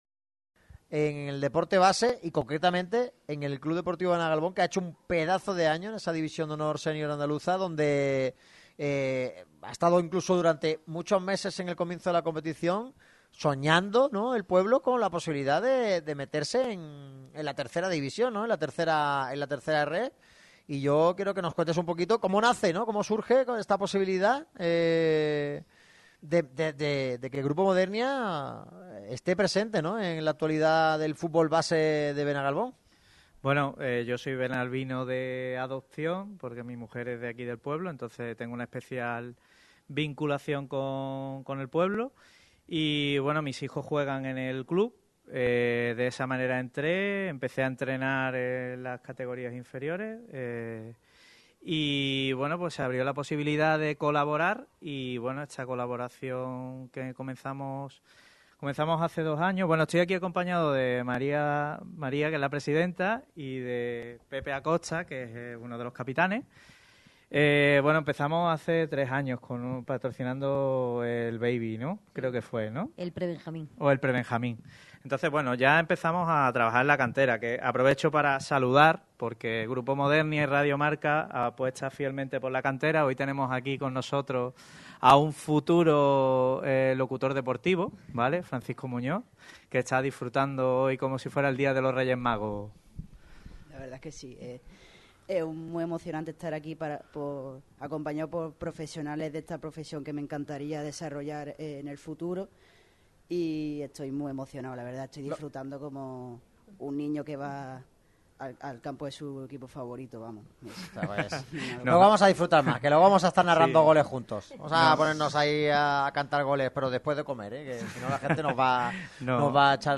La radio del deporte malagueño se ha desplazado este miércoles hasta el restaurante Galbun, situado en la localidad de Benagalbón.
De la mano de Grupo Modernia, Radio MARCA Málaga ha disfrutado de un nuevo programa marcado por la actualidad de los dos gigantes del deporte malagueño, Málaga CF y Unicaja Baloncesto. Combinado con una amena tertulia con la cúpula directiva y miembros de la plantilla del CD Benagalbón, club con el que colabora Grupo Modernia como patrocinador principal.